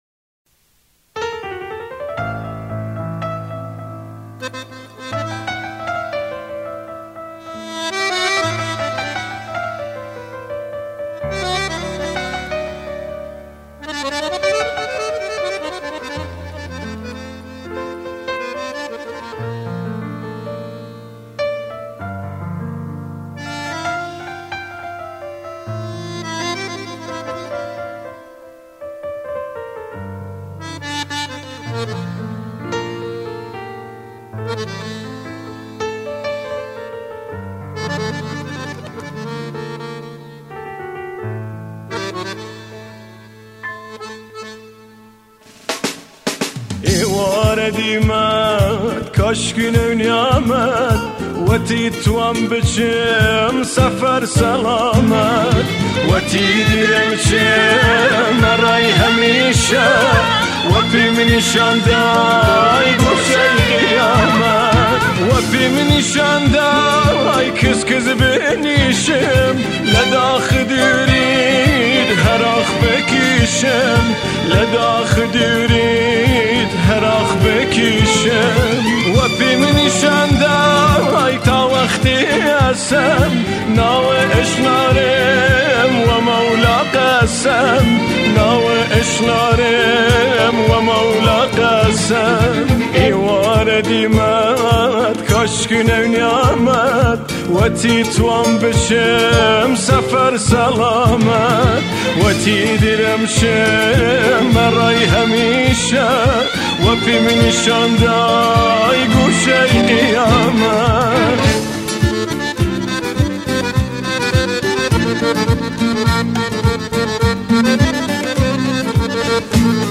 آهنگ غمگین به زبان شیرین کردی